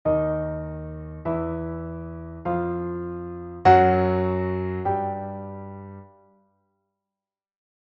der Ton Fis, Notation
der-Ton-Fis.mp3